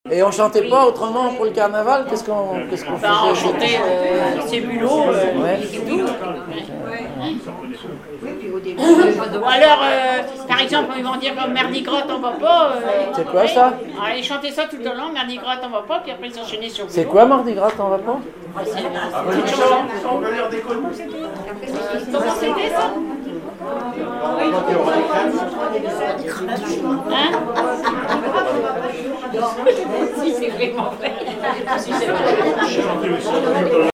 Informateur(s) Club d'anciens de Saint-Pierre association
Catégorie Témoignage